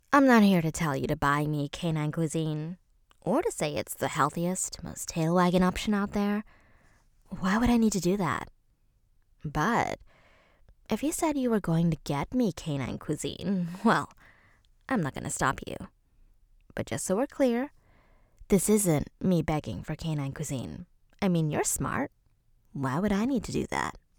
standard us | natural